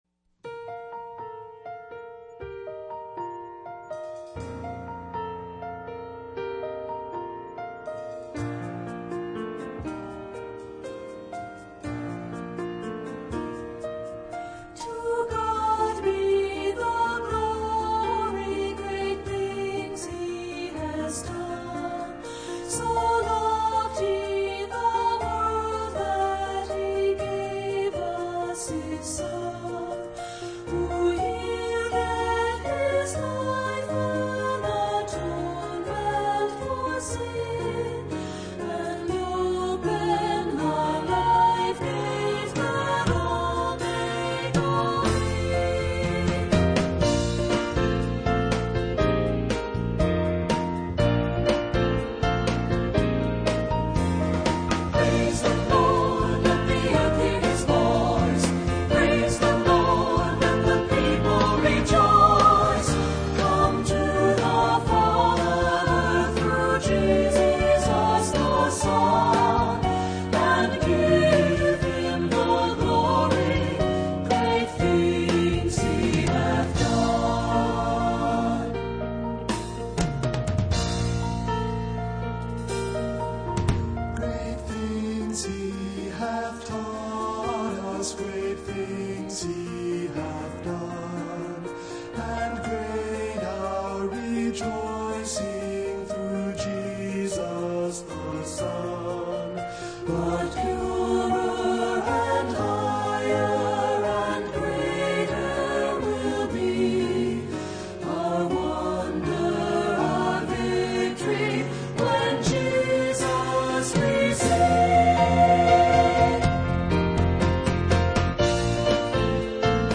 Voicing: SAT